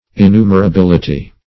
Search Result for " innumerability" : The Collaborative International Dictionary of English v.0.48: Innumerability \In*nu`mer*a*bil"i*ty\, n. [L. innumerabilitas.]
innumerability.mp3